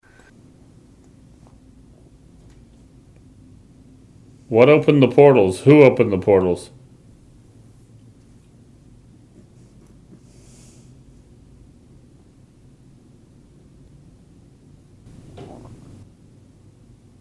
Immediately following this event, we captured an EVP from the entity in this closet, further validating this extraordinary paranormal evidence. In response to the question “Who opened the portal?” the entity responds with a simple “Portal.”
mantec-portal.wav